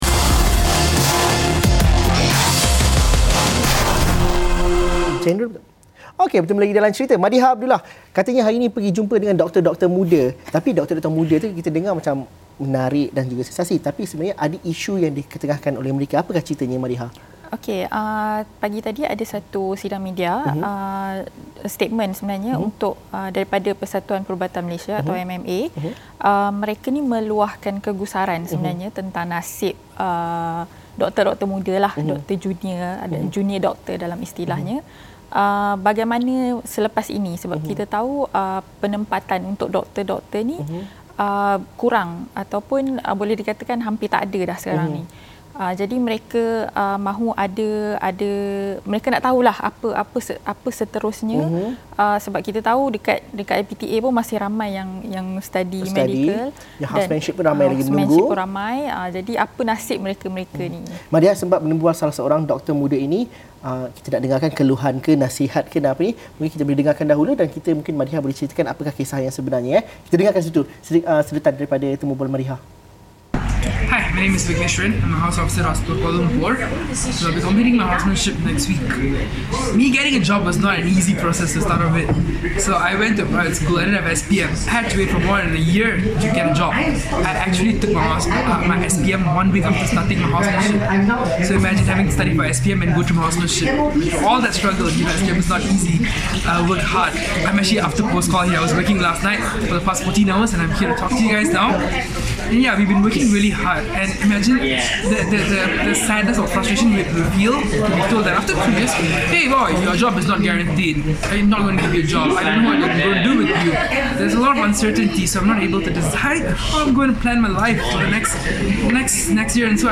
Wartawan